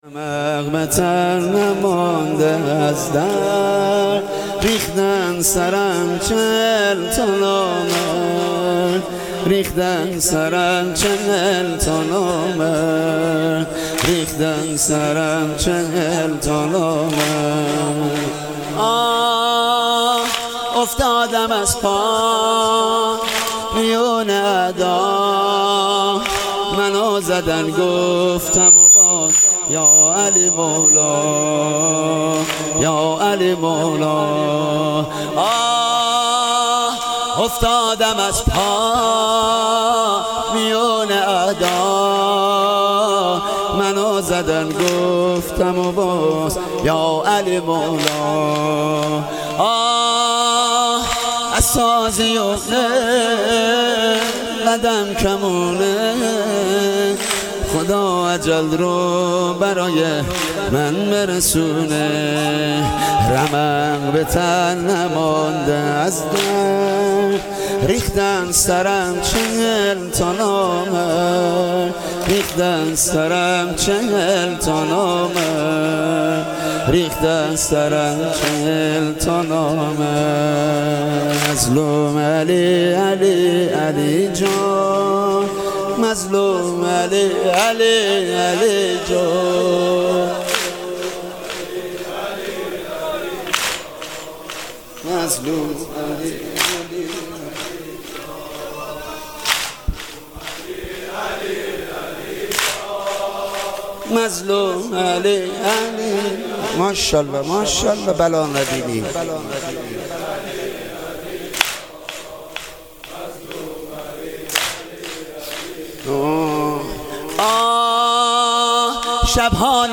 واحد مداحی